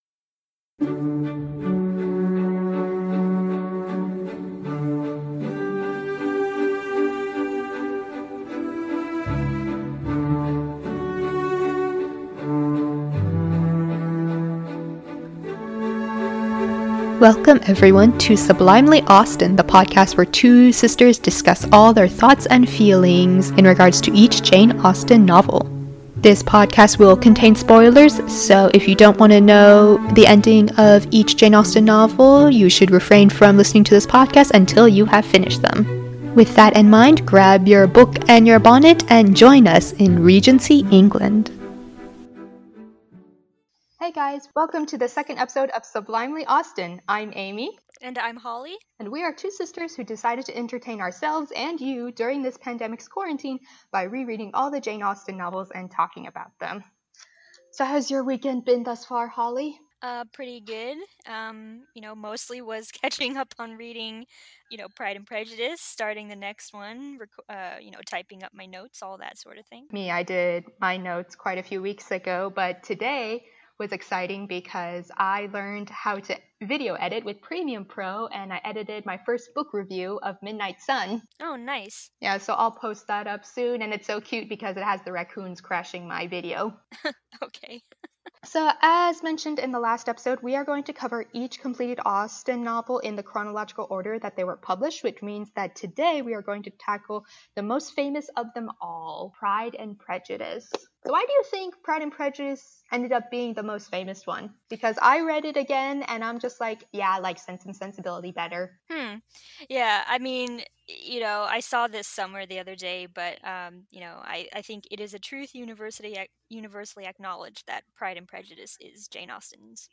We are two sisters who dedicated to entertain ourselves and you during the pandemic’s quarantine by rereading all the Jane Austen novels and talking about them. Today we’re discussing Jane Austen’s second publication, Pride and Prejudice.